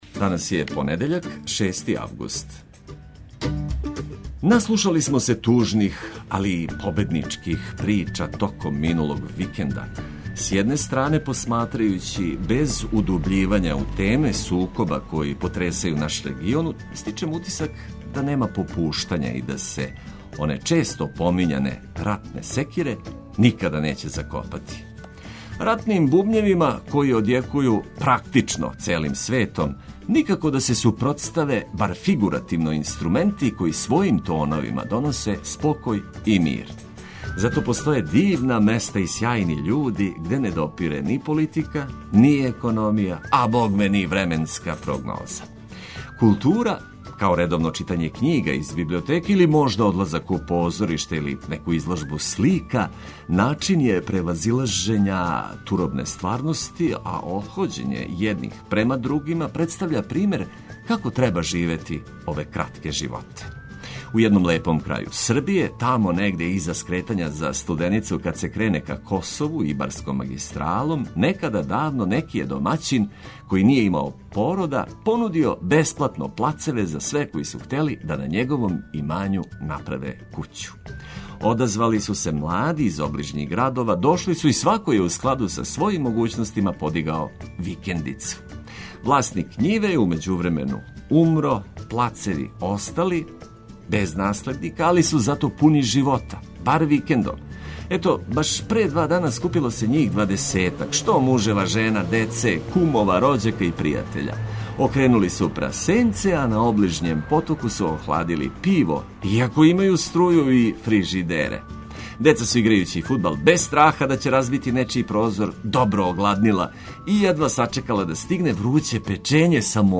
У нашем друштву се уз музику и важне информације лакше започиње дан.